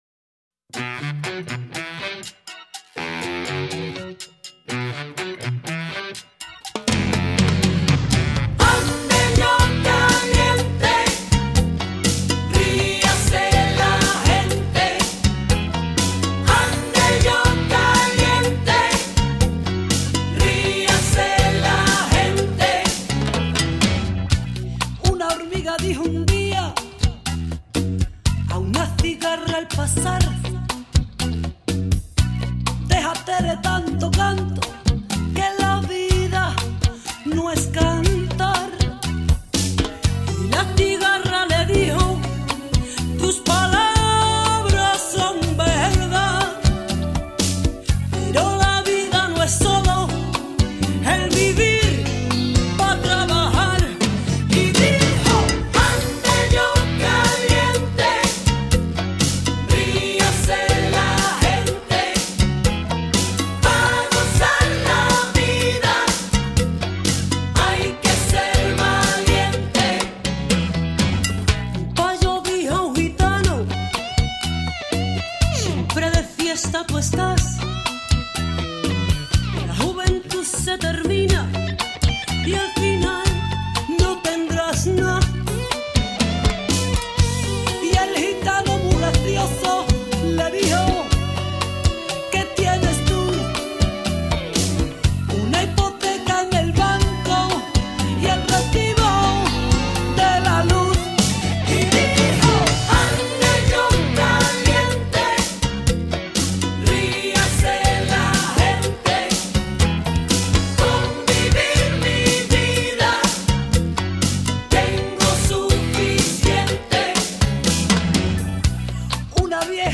03 Cha-Cha-Cha